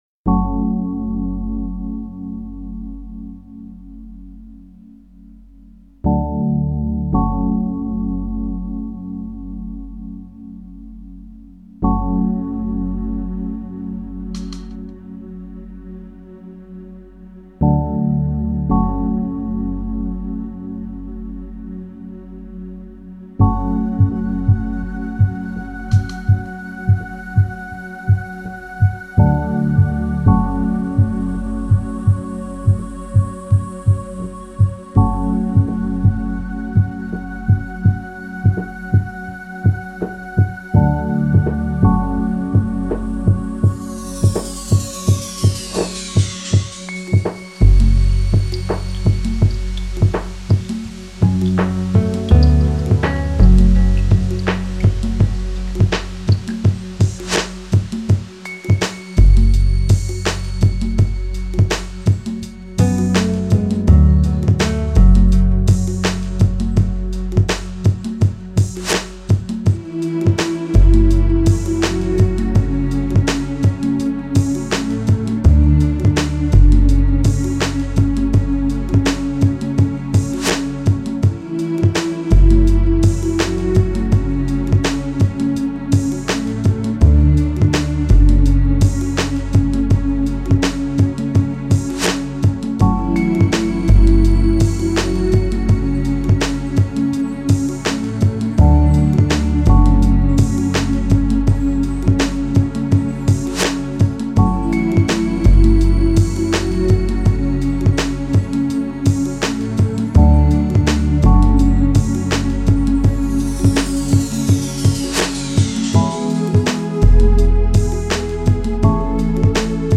Some Quiet Meditation Music to Bless You: Electra